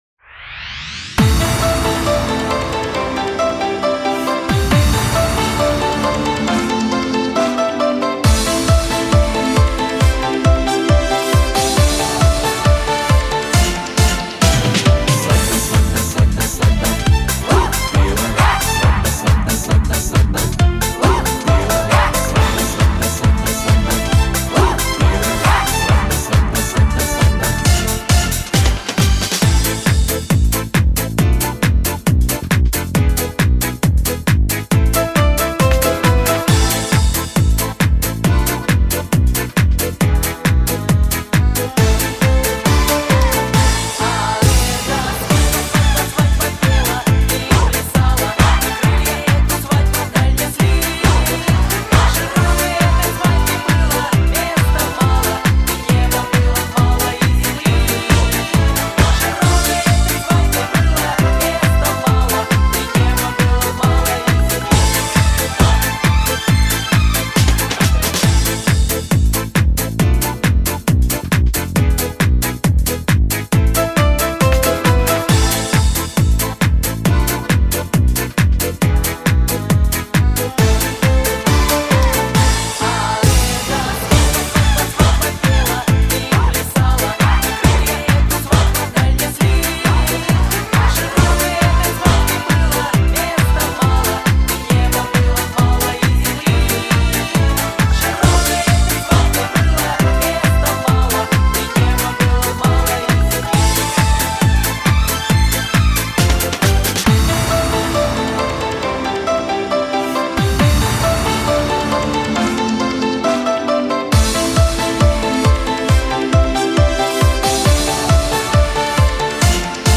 Свадебные